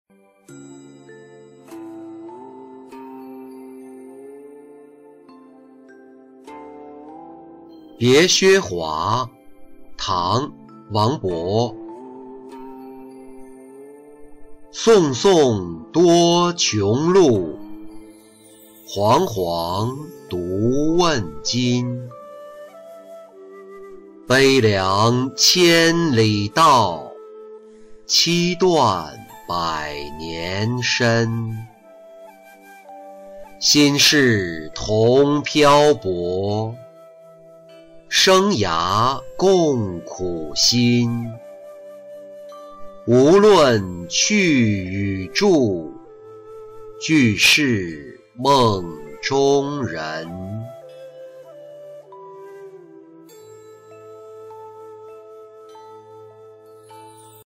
别薛华-音频朗读